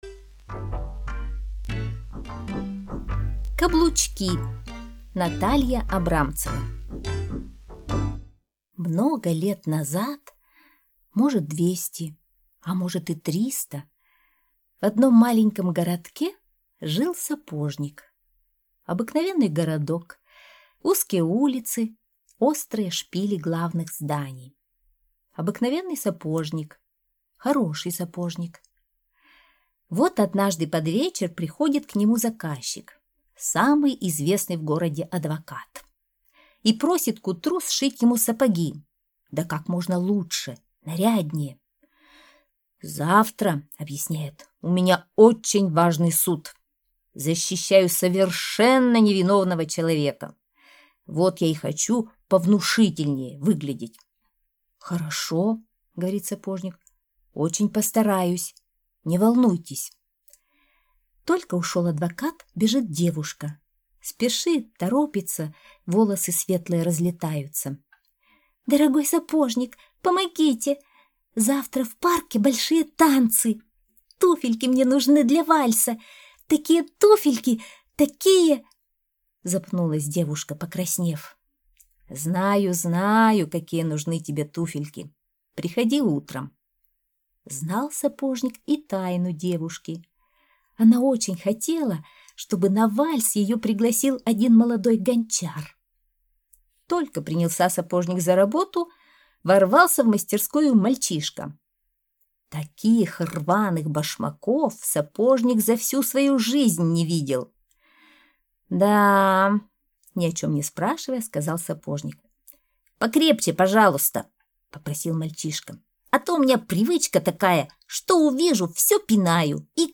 Каблучки - аудиосказка Натальи Абрамцевой - слушать скачать